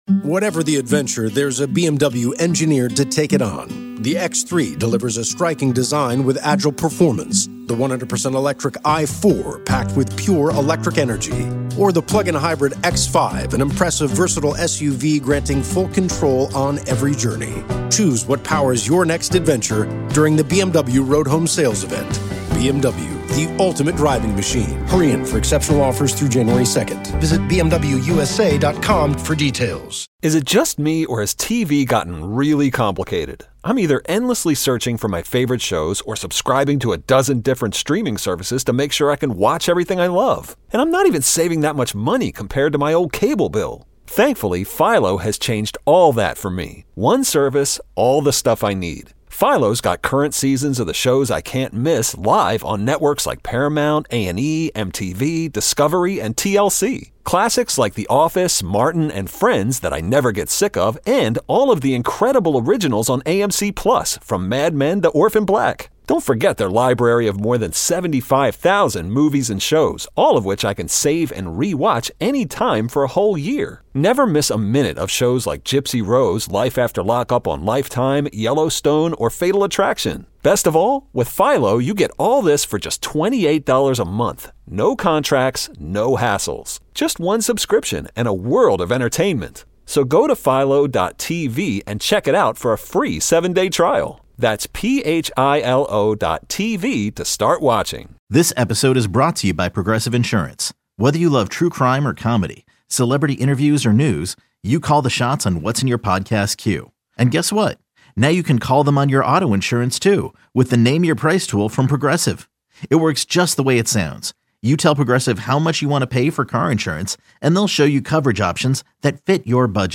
your calls